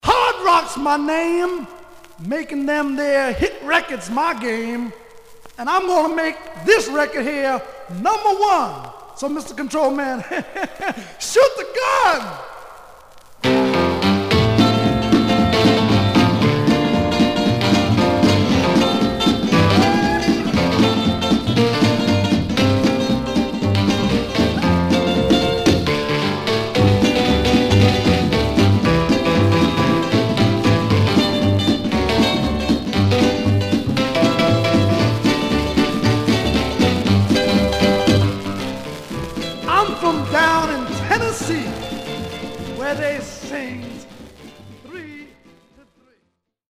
Condition Surface noise/wear Stereo/mono Mono
R & R Instrumental